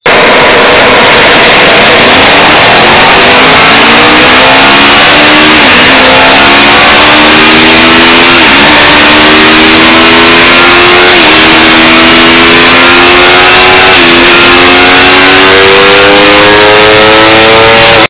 Soundlink RSV4 LeoVince